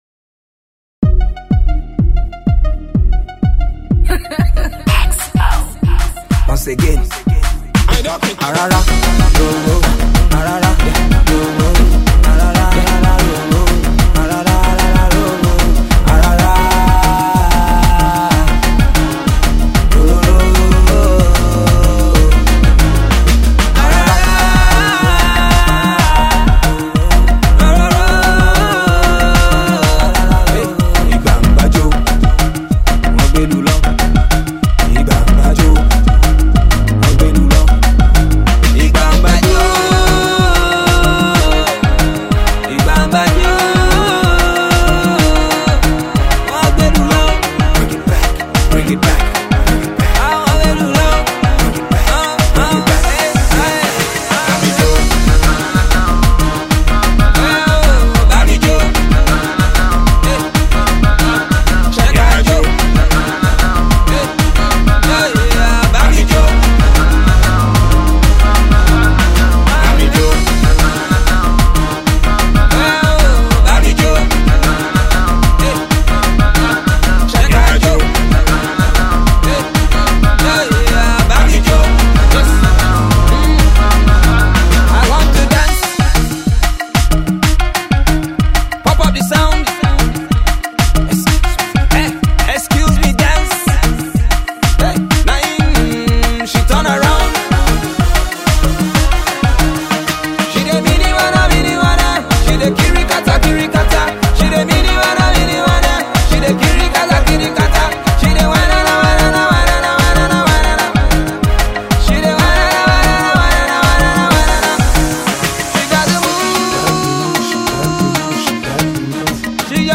Indigenous Pop
Yoruba Music